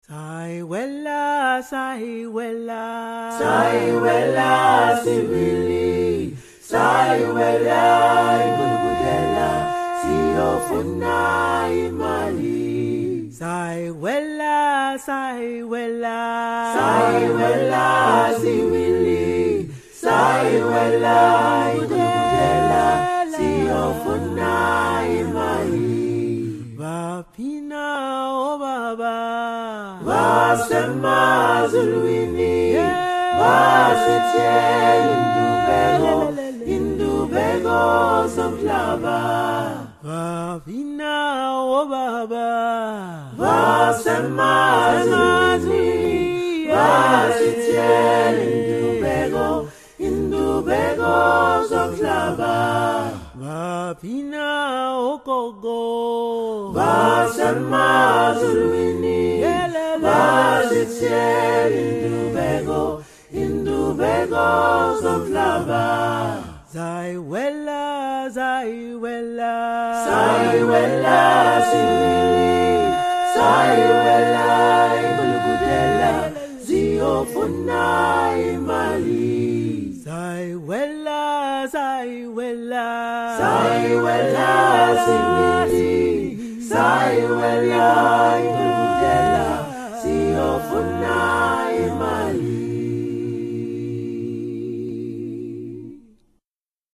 recording of song in a mystery language.
I can’t hear any of the click consonants common in Xhosa or Zulu but it definitely sounds Southern African to me.
However, the accent is much “softer” sounding than any Zulu I have heard, especially the voiced bilabial fricatives where I would expect to hear Zulu’s implosive b. I wonder if this could be Zimbabwean siNdebele or perhaps Nrevhele of the Transvaal…?